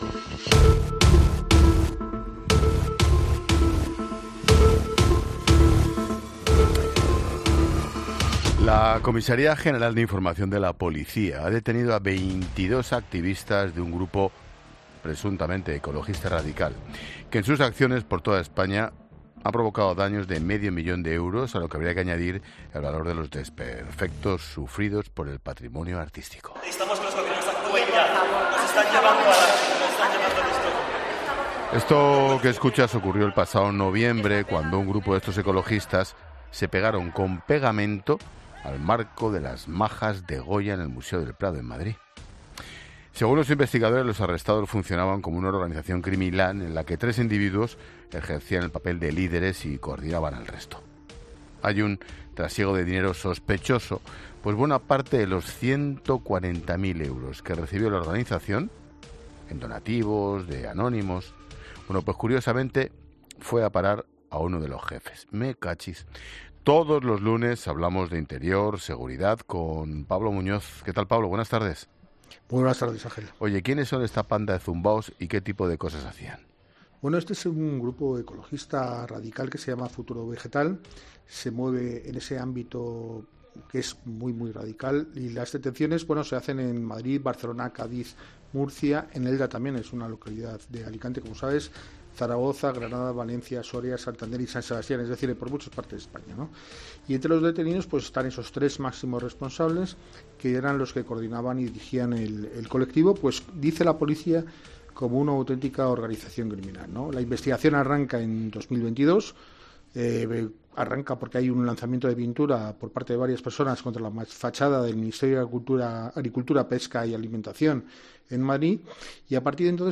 periodista de ABC, explica en La Linterna cuál es el delito del que acusan al grupo ecologista radical